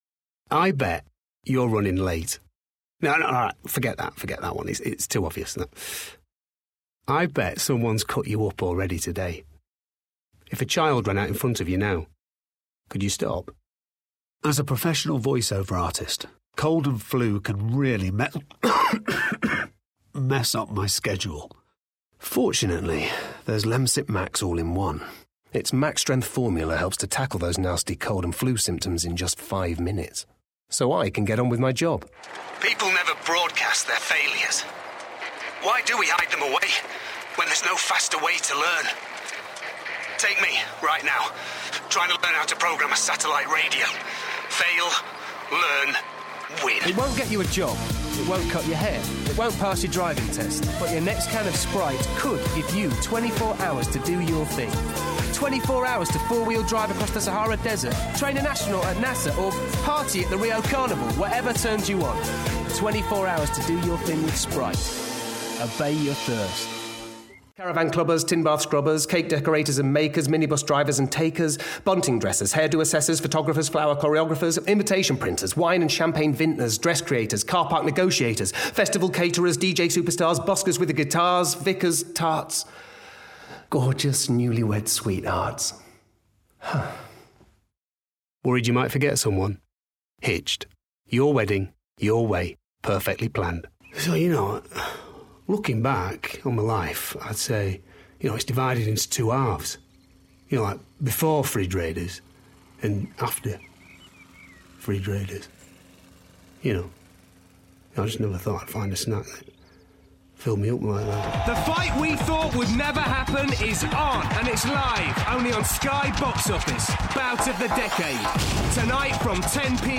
Commercial
30s-40s - warm, versatile, cheery
Northern (English), Yorkshire, Lancashire, Standard English/RP, London/Cockney, American, Mancunian, Irish, Newcastle/Geordie
Actors/Actresses, Corporate/Informative, Natural/Fresh, Smooth/Soft-Sell, Character/Animation, Comedy, Upbeat/Energy, Mature/Sophisticated